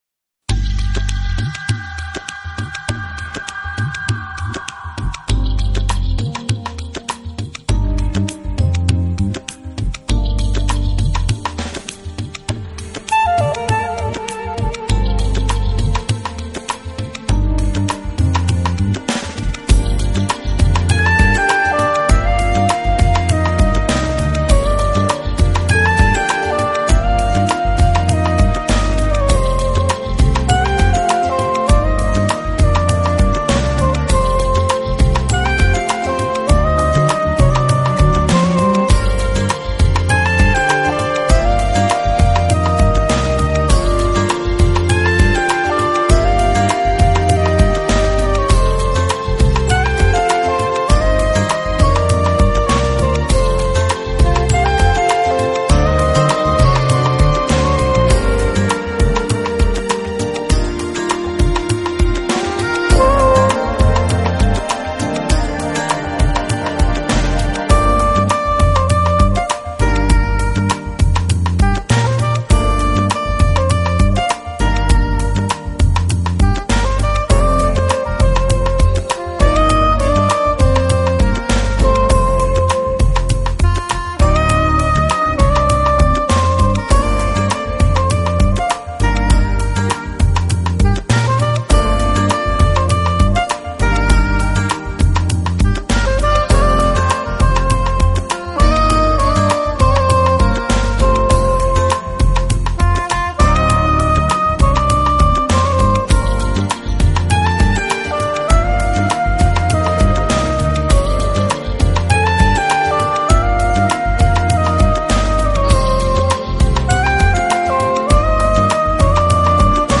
Genre: Smooth Jazz